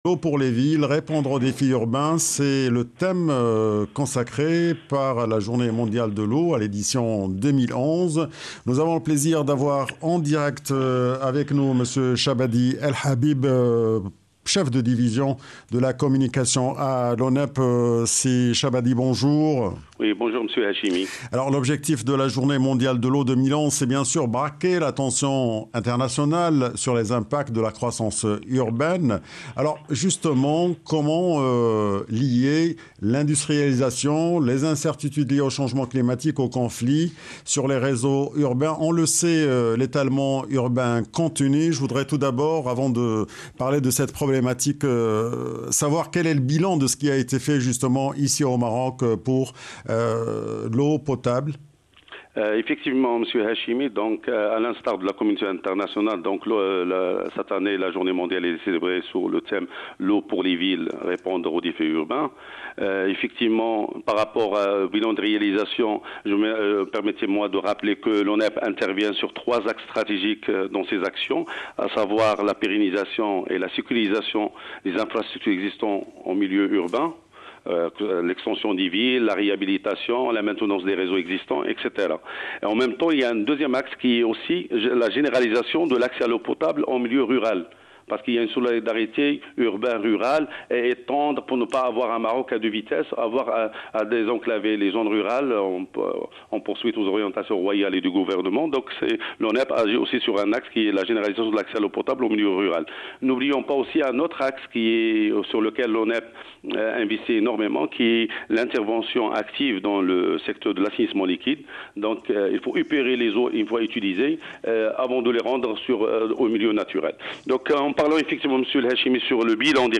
Reportage Radio